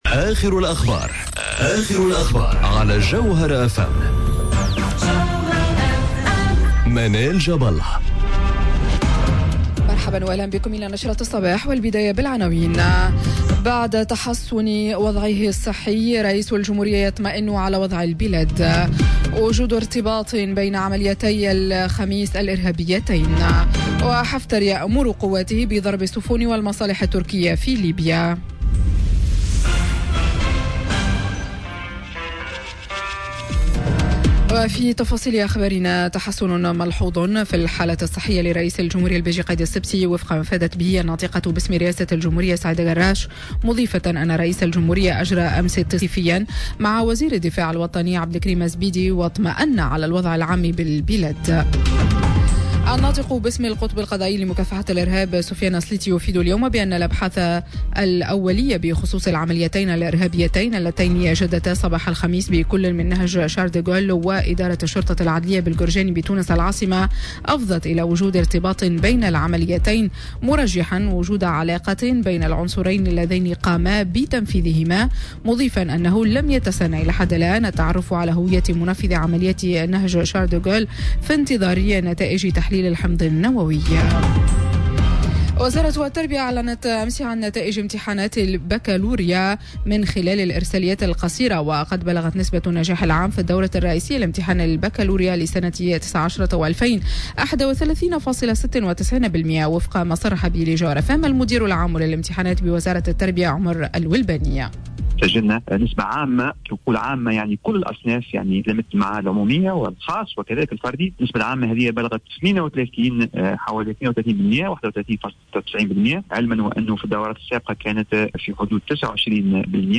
نشرة أخبار السابعة صباحا ليوم السبت 29 جوان 2019